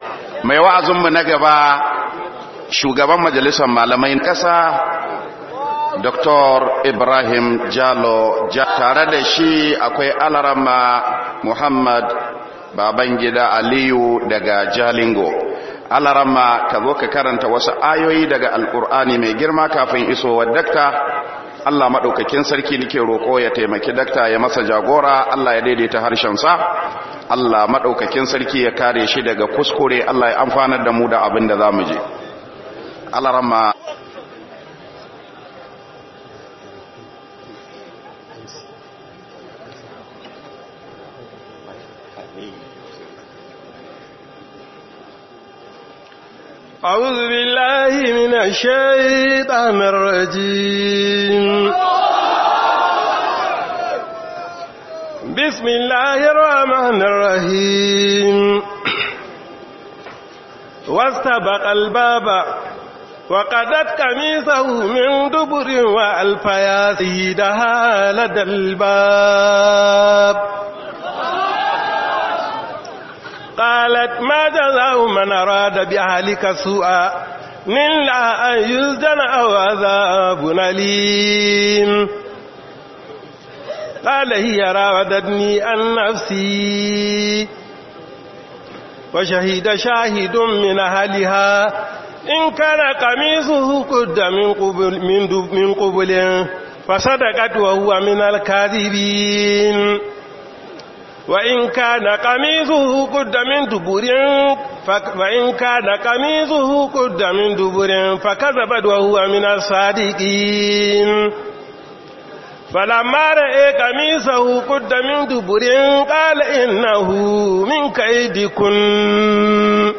Raddi ga Yan Kalo Kato - MUHADARA